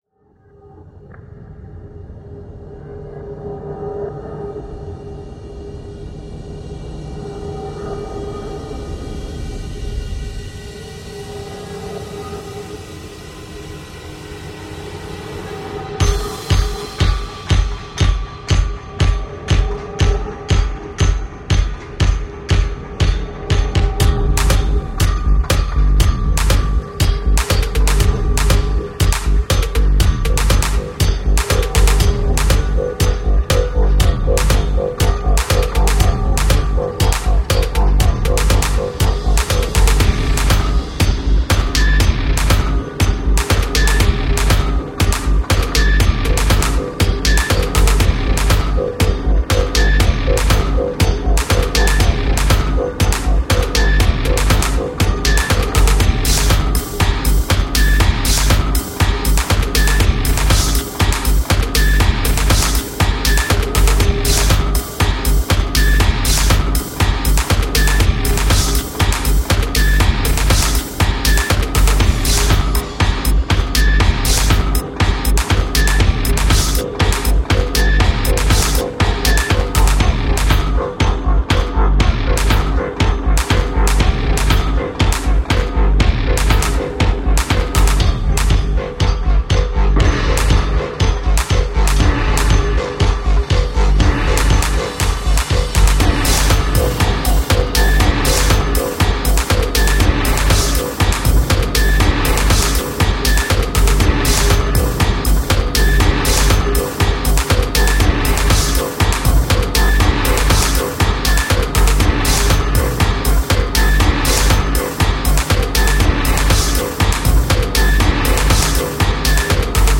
Reykjavik protest reimagined